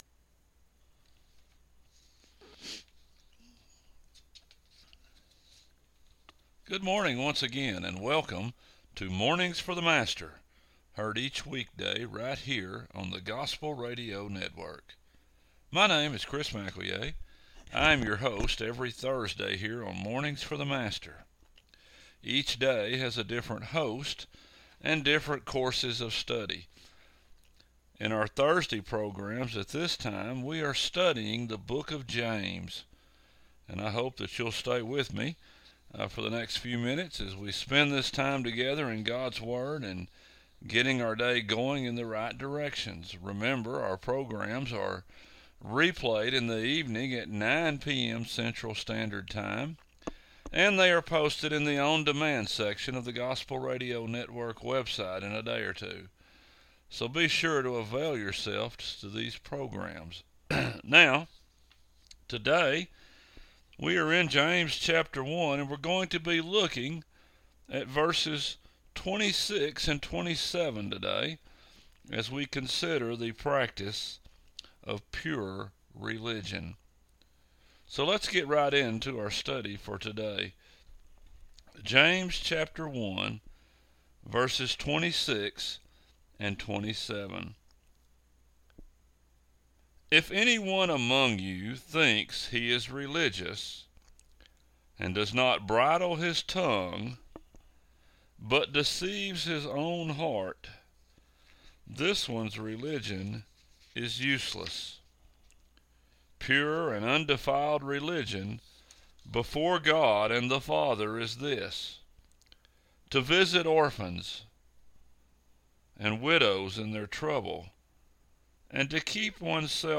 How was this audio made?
Program Info: Live program from the TGRN studio in Mount Vernon, TX